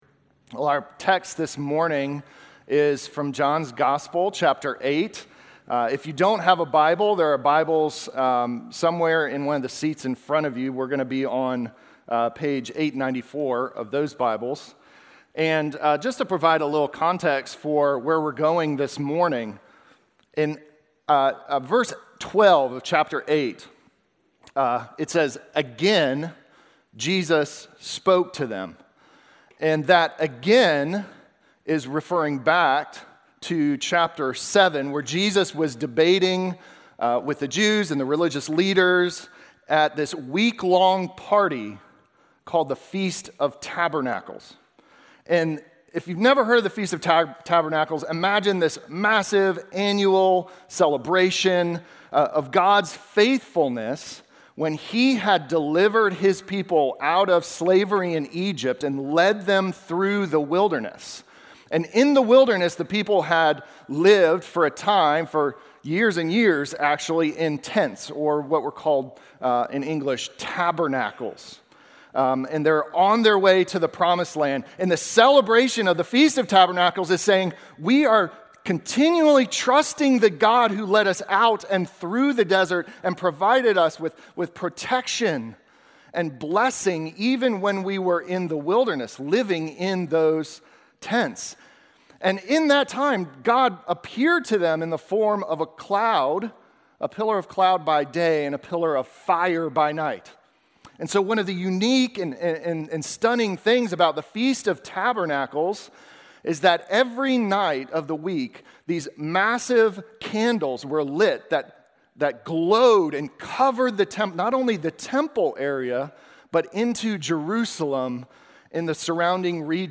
Sermon from September 7